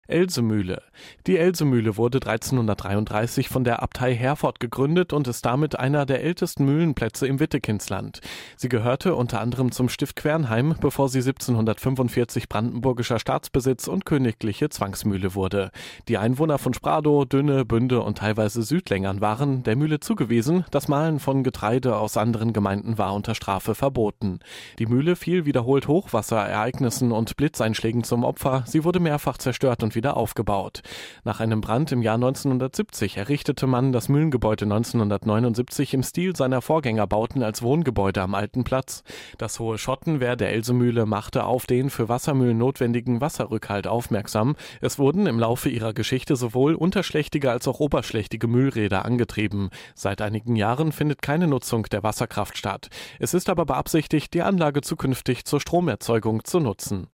Audioguide Bauernbad-Radelroute Bünde
Mit dem Audioguide zur Bauernbad-Themenroute in Bünde erhalten Sie gesprochene Informationen zu den Besonderheiten entlang der Route.